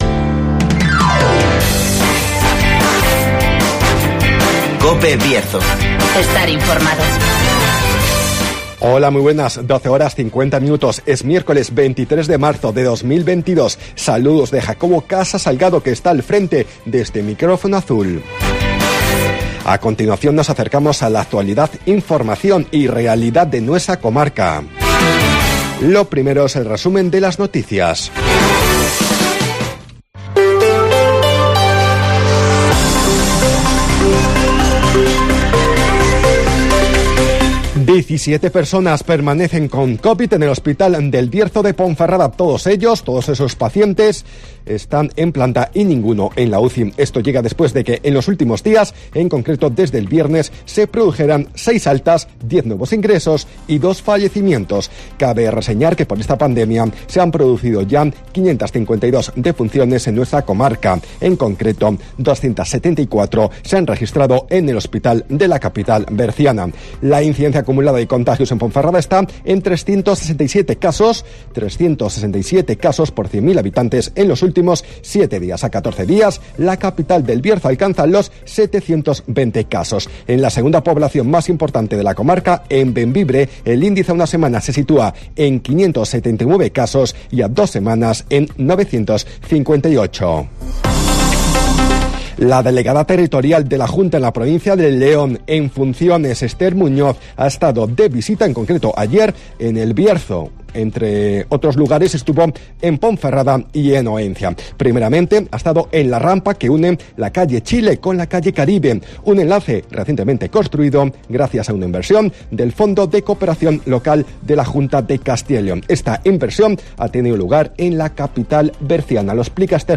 AUDIO: Resumen de las noticias, El Tiempo y Agenda